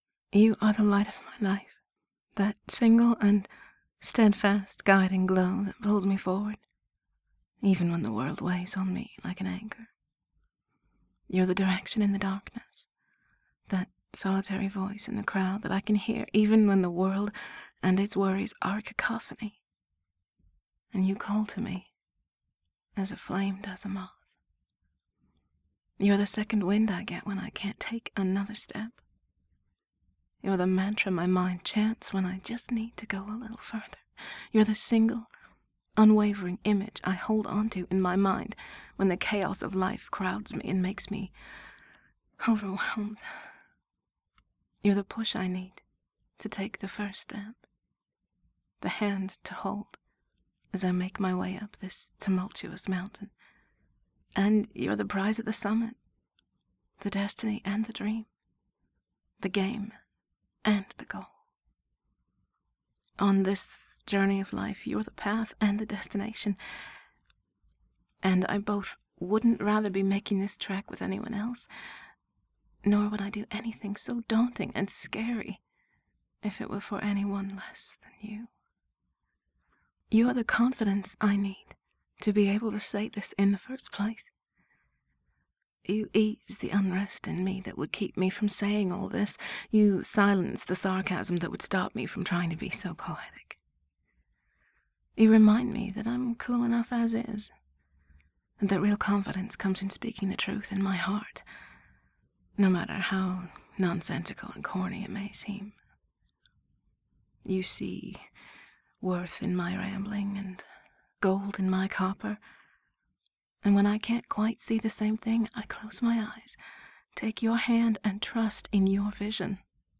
And there's something about your voice that makes it extra loving and sweet!!!